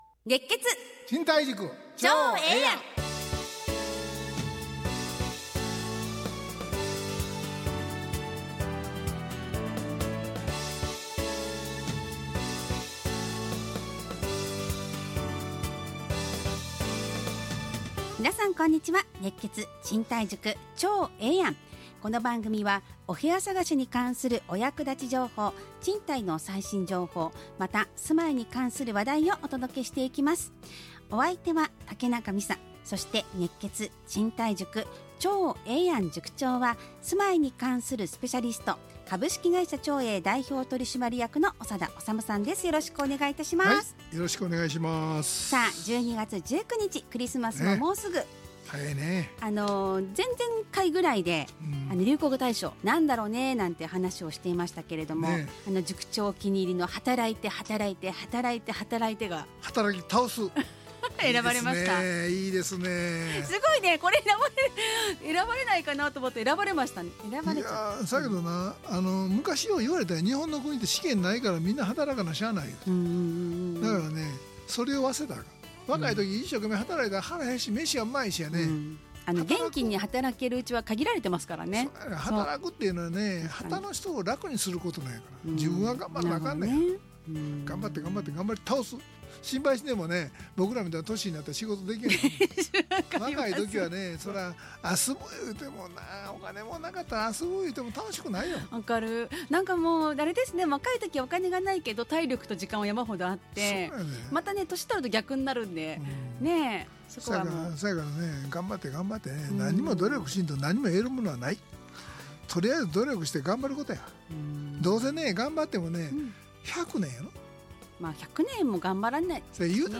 ラジオ放送 2025-12-19 熱血！